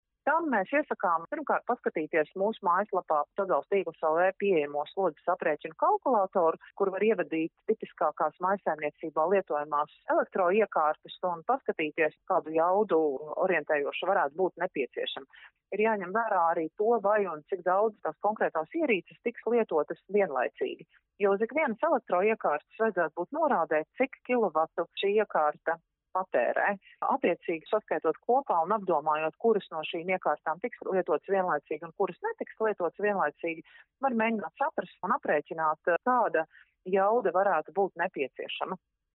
RADIO SKONTO Ziņās par to, kā izvērtēt nepieciešamo pieslēguma jaudu